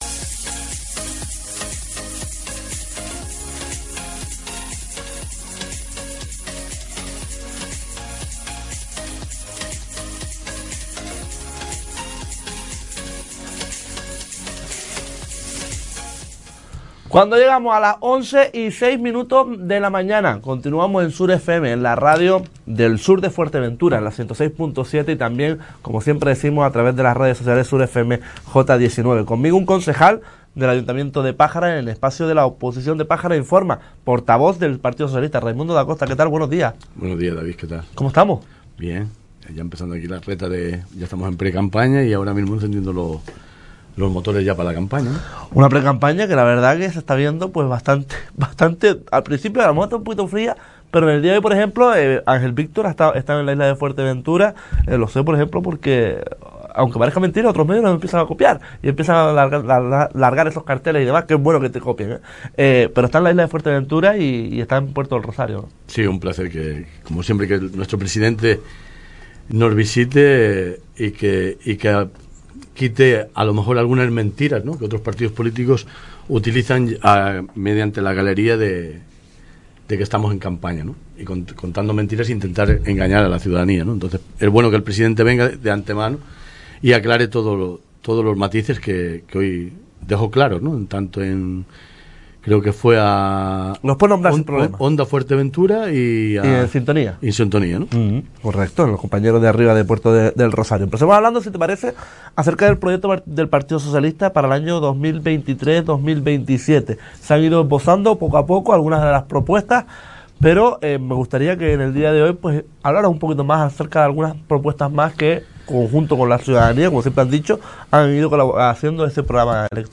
Puedes volver a escuchar la entrevista realizada al portavoz y concejal del PSOE de Pájara, Raimundo Dacosta.